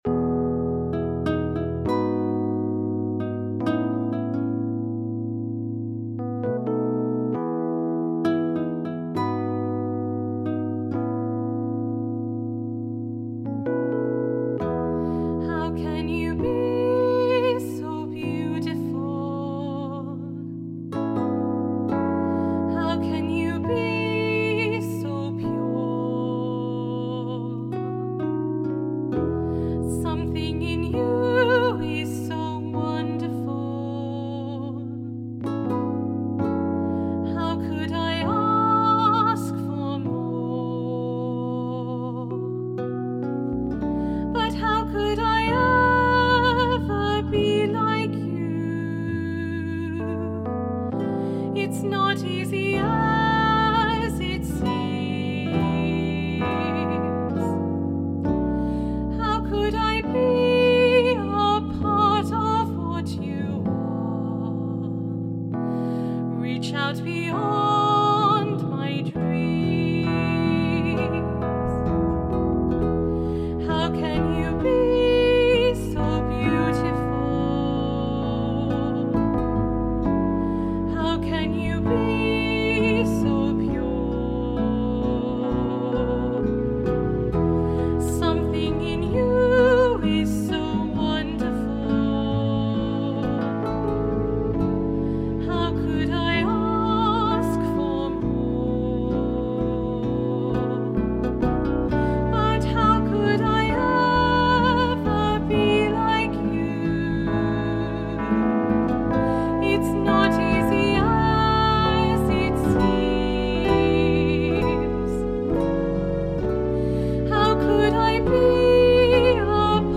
Amazing Place - Vocals